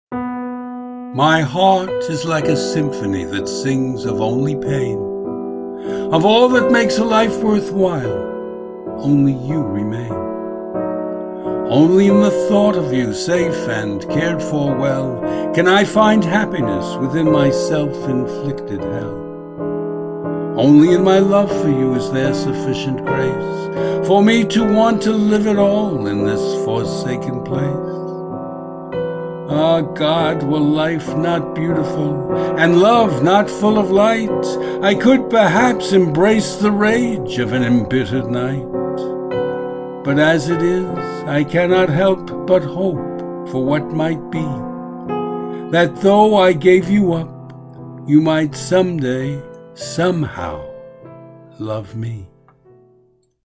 Audio and Video Music:
E Minor Prelude.
By Frederic Chopin.